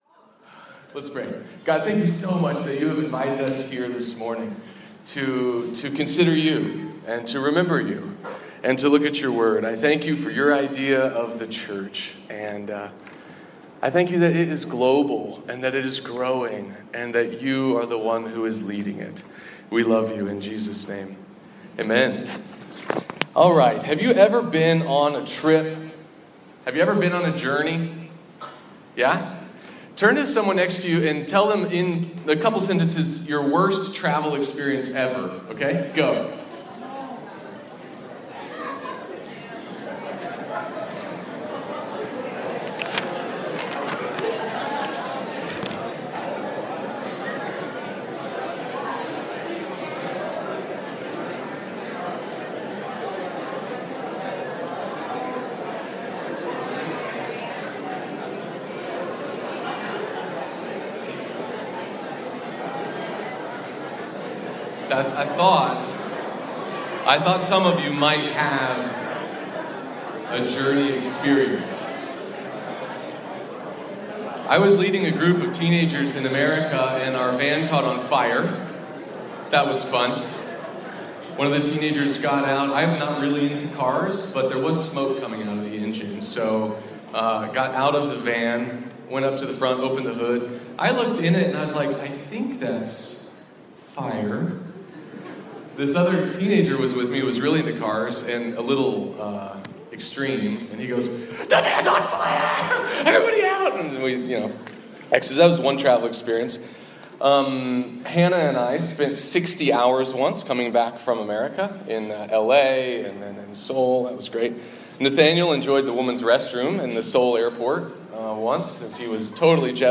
This message was recorded at ICA-Cambodia, October 2018. Towards the end, the congregation sings a bit, and then the message continues: Songs of Ascent, part 1